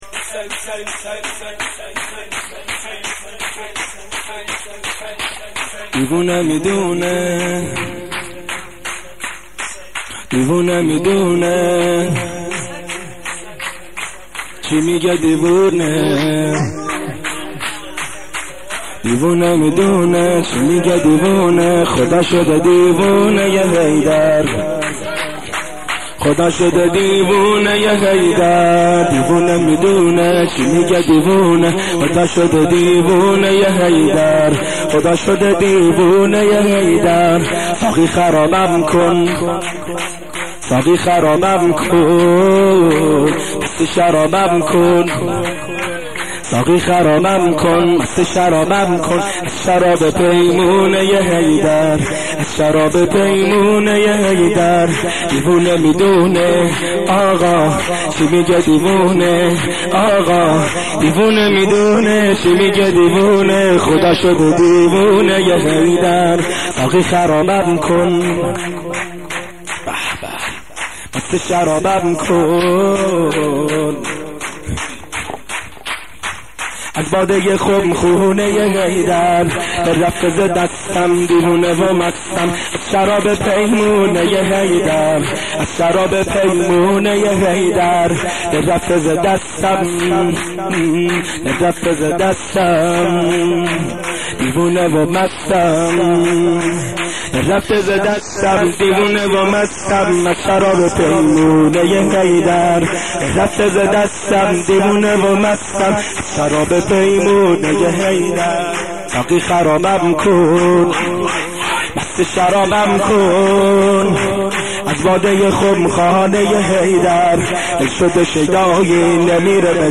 شور گلچین مداحی 1382